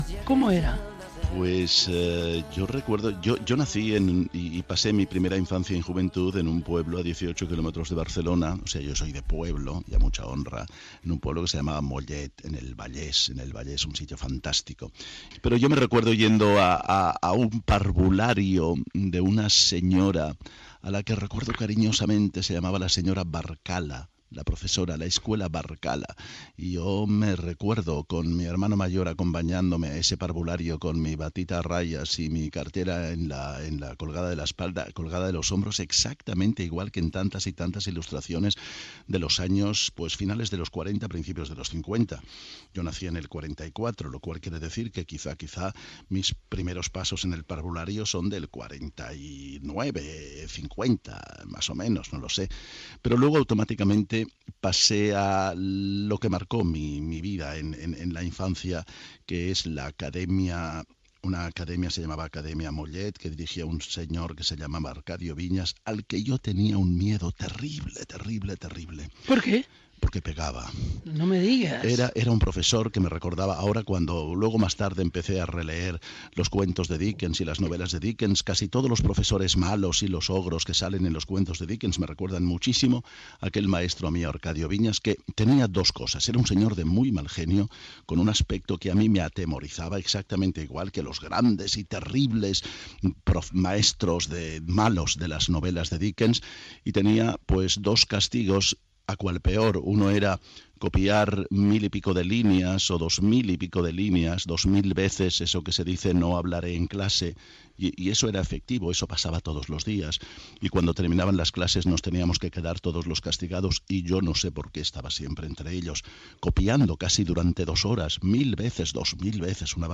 Entrevista a l'actor Josep Maria Pou que recorda la seva època de parvulari i escolar a Mollet del Vallès i els seus inicis teatrals